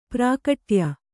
♪ prākaṭya